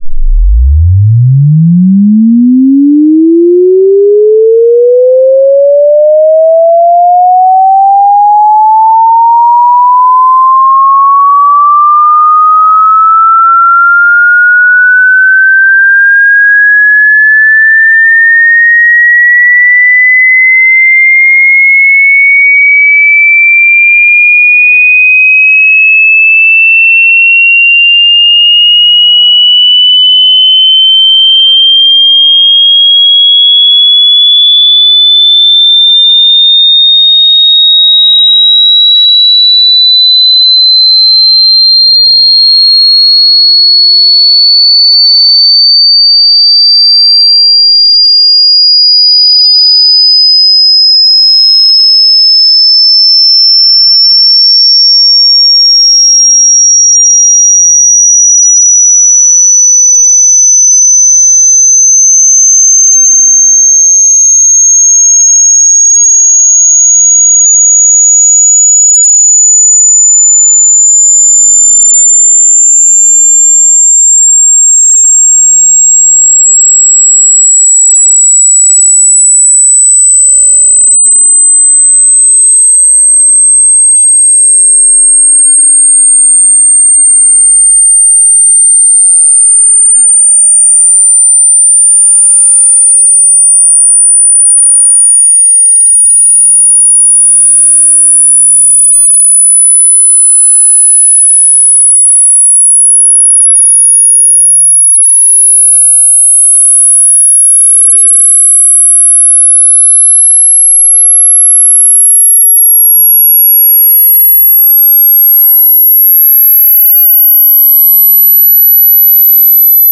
Test your ears with a chirp increasing from 0 to 20 kHz with an
second it is increasing 100 Hz so you can determine the frequency bt
CHIRP.WAV